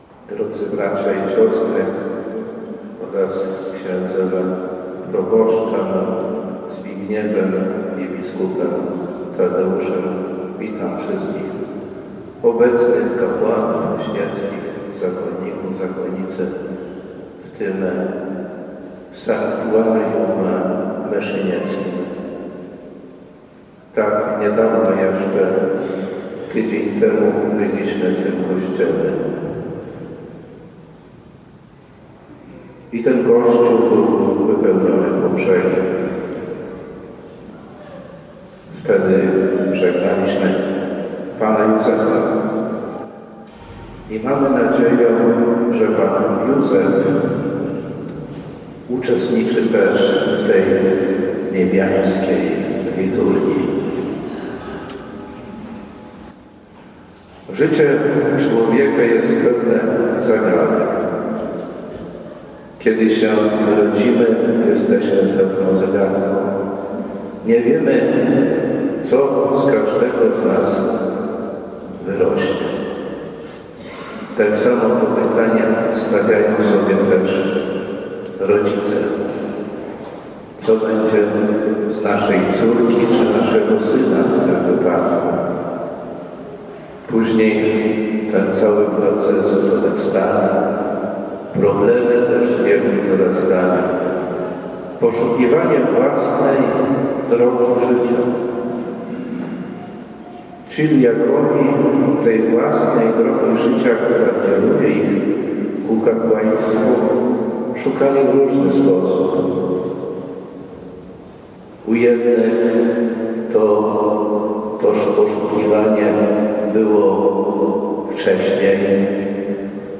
W sobotę 10 maja odbyły się świecenia diakonatu diecezji łomżyńskiej. Uroczystej Mszy Świętej o g. 10.00 w Bazylice Mniejszej w Myszyńcu przewodniczył ks. bp Janusz Stepnowski wraz z ks. bp. Tadeuszem Bronakowskim i zgromadzonym duchowieństwem.